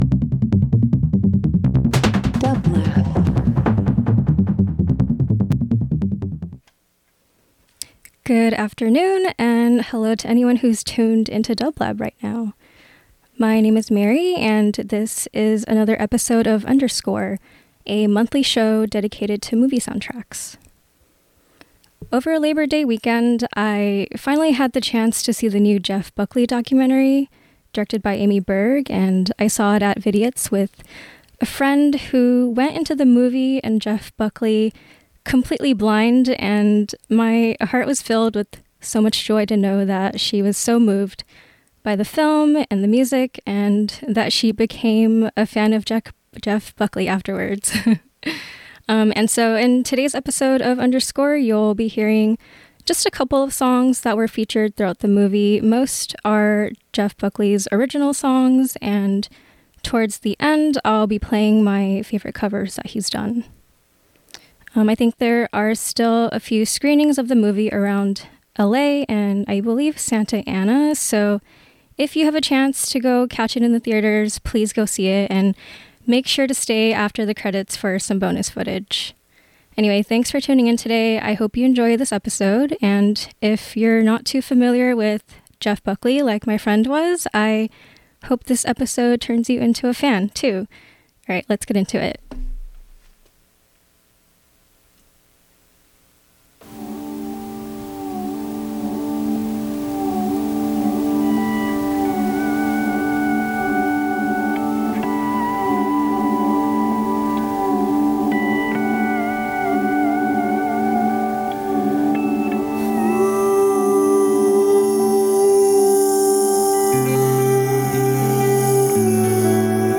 Folk Rock Soul Soundtracks